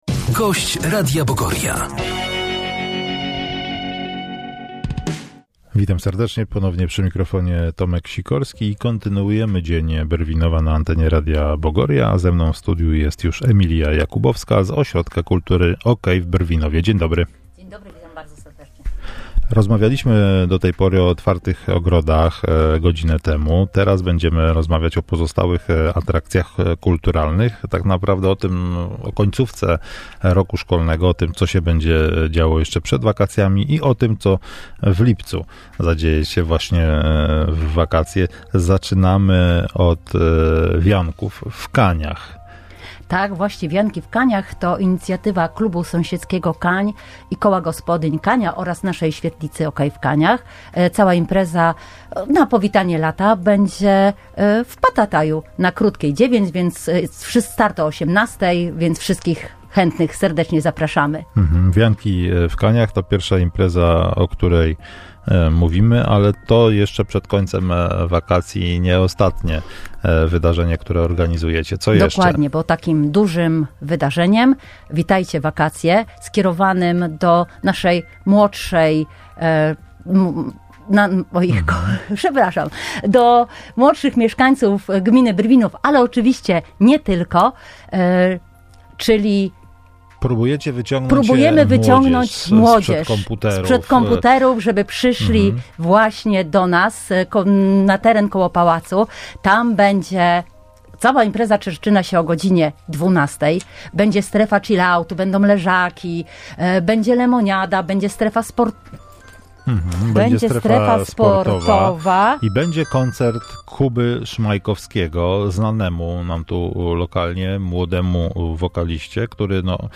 Wywiady w Radio Bogoria – czerwiec 2025